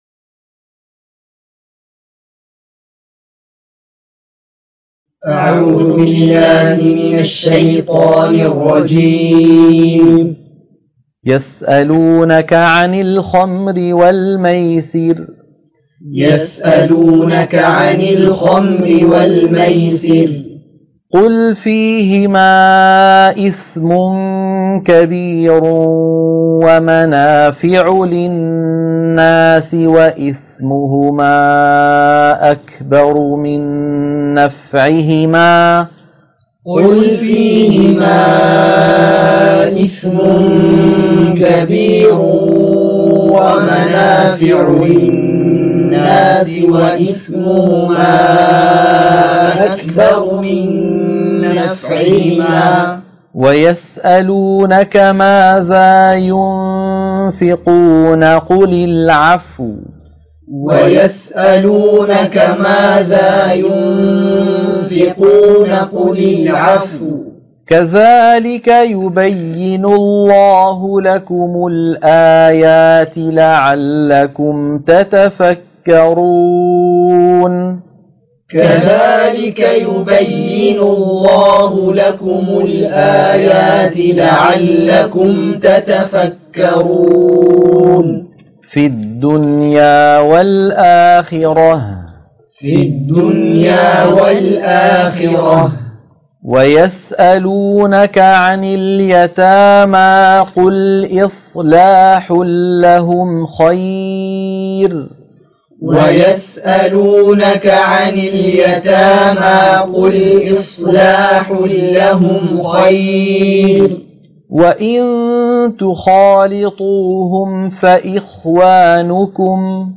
تلقين سورة البقرة - الصفحة 35 _ التلاوة المنهجية - الشيخ أيمن سويد